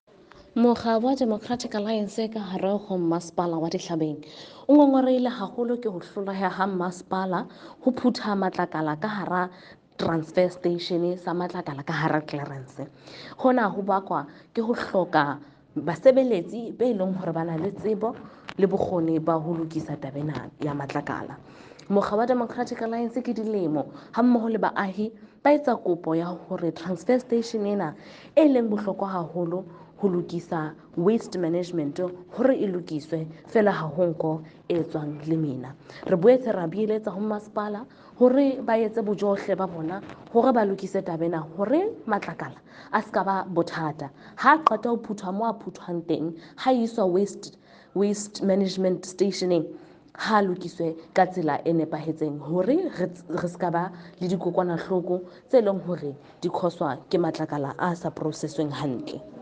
English soundbite by Cllr Irene Rugheimer and
Sesotho by Karabo Khakhau MP.